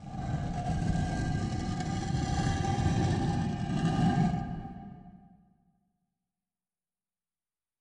Ощутите загадочную атмосферу пещер с нашей коллекцией натуральных звуков.
Звук отодвигаемой двери или камня в пещере